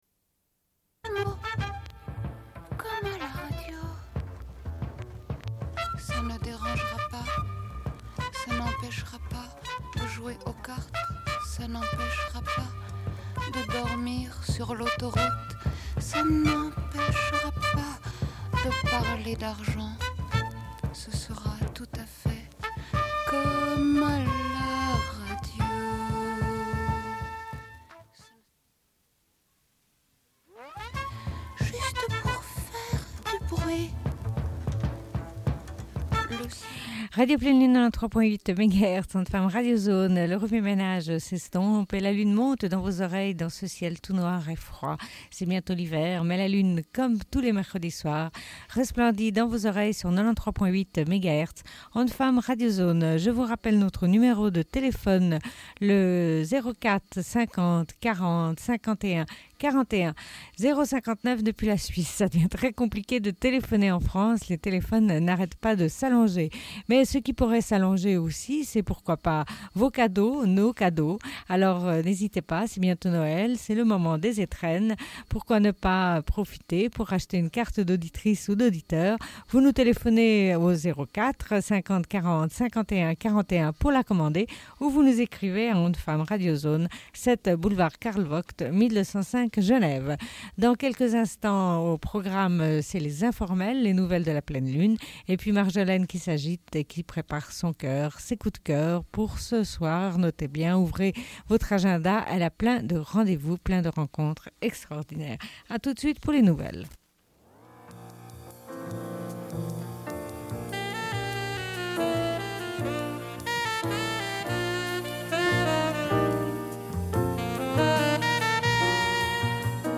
Bulletin d'information de Radio Pleine Lune du 13.11.1996 - Archives contestataires
Une cassette audio, face B